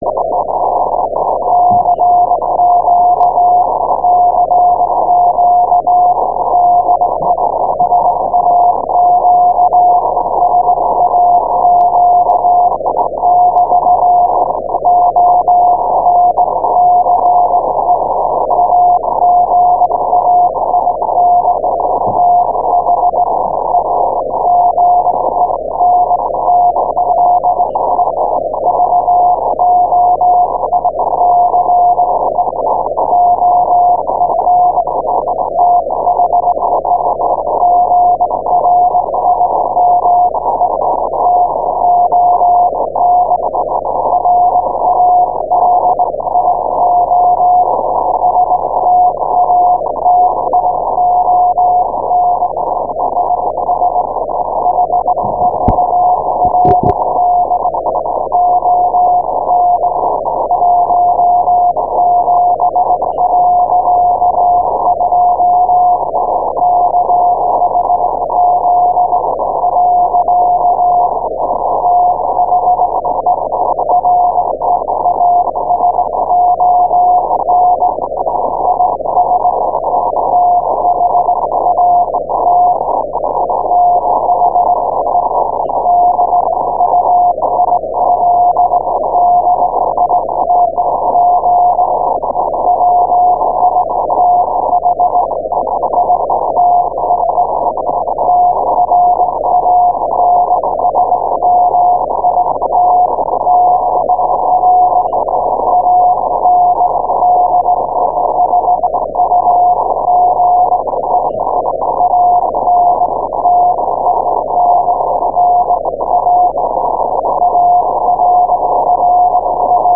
Click HERE to hear the sound of the Grimeton station on 17
The same sound file (14 minutes, Mpeg, 0.9 Mb .mp2) but with limited quality HERE